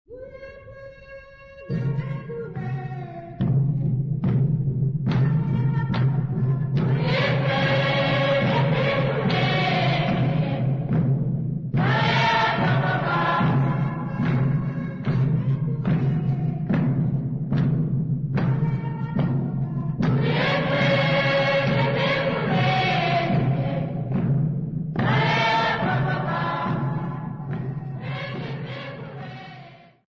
Tondoro church music workshop participants
Sacred music Namibia
Choral music Namibia
Ngoma (Drum) Namibia
field recordings
Practise of new song at Tondoro church music workshop with drum and clapping accompaniment.